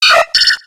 Cri de Togepi dans Pokémon X et Y.